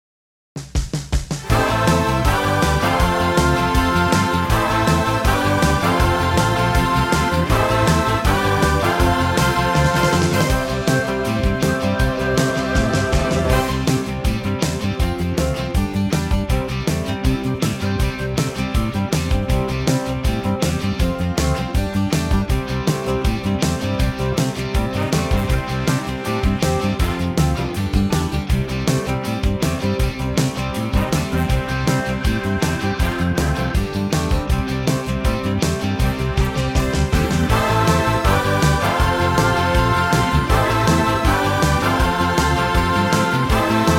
drum fill and into an 8 bar intro
key - C - vocal range - G to E
in a super punchy big arrangement, brighter in tempo